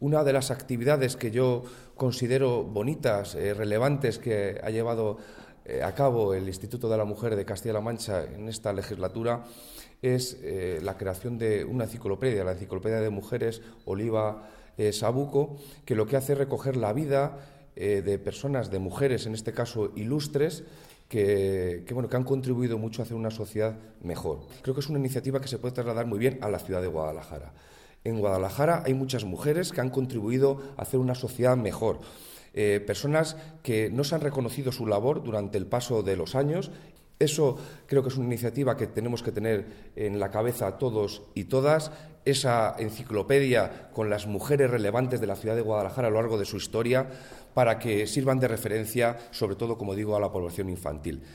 El delegado de la Junta en Guadalajara, Alberto Rojo, habla de la importancia de visibilizar a las mujeres ilustres a las que la historia no ha reconocido para crear referencias que contribuyan a avanzar en materia de igualdad.